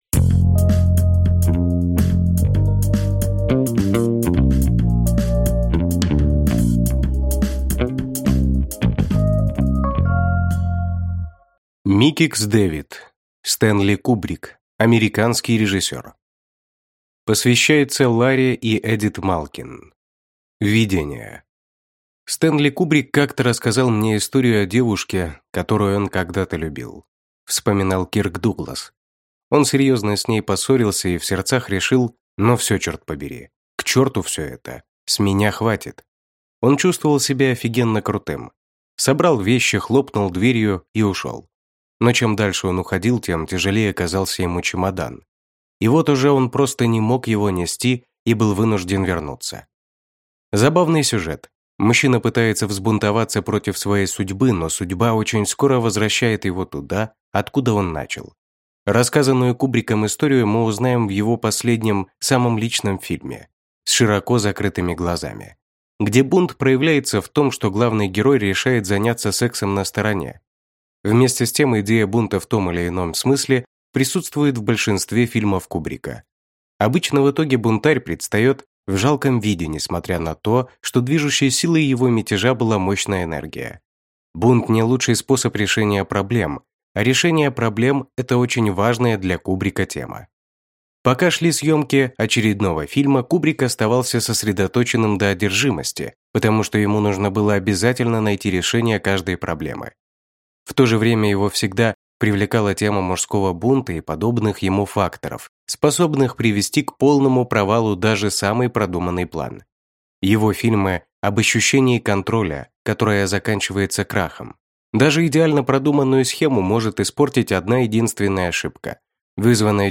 Аудиокнига Стэнли Кубрик. Американский режиссер | Библиотека аудиокниг